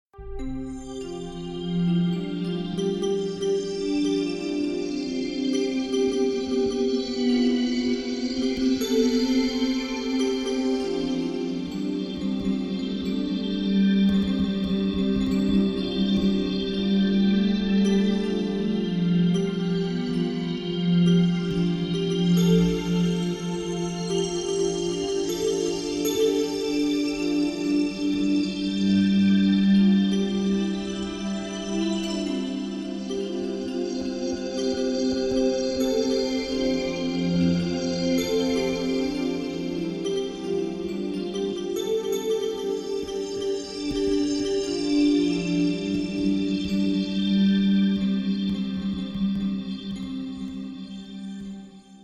The music you will be hearing is the improvised